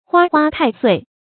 花花太岁 huā huā tài suì
花花太岁发音